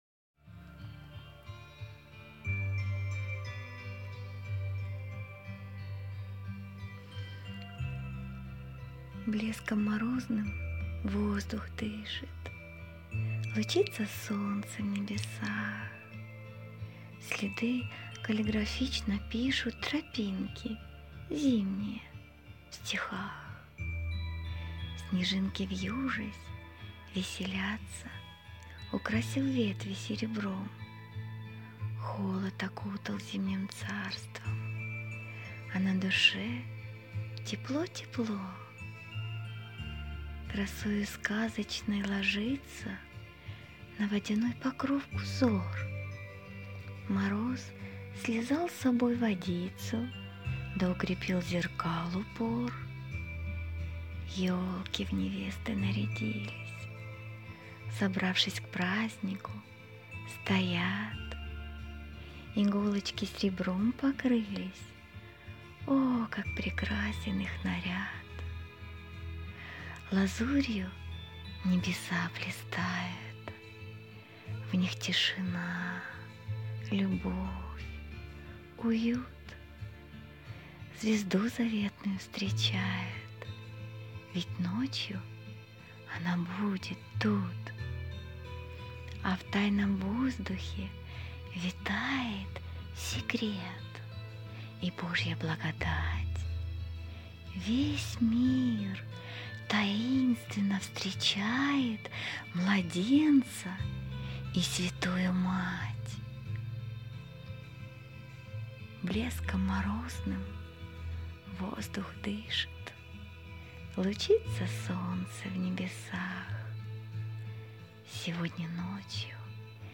❤❤❤ ДЕНЬ ПЕРЕД РОЖДЕСТВОМ… (стих начитан)